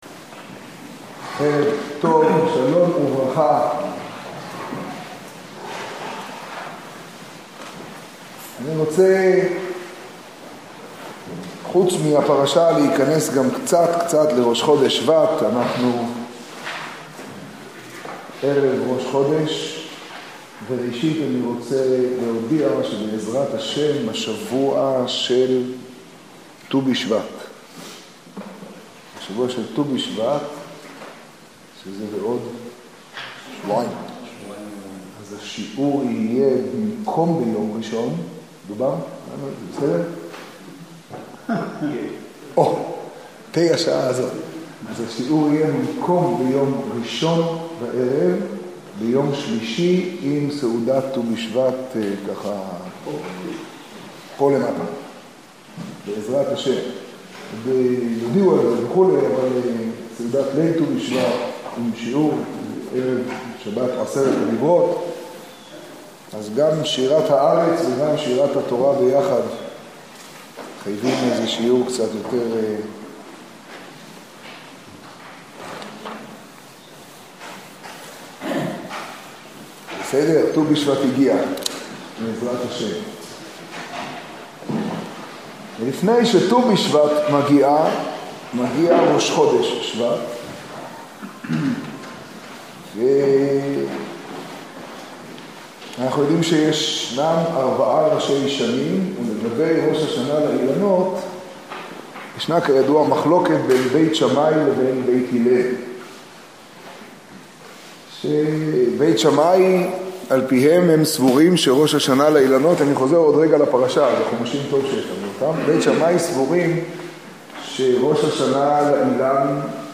השיעור בירושלים, פרשת בא תשעה.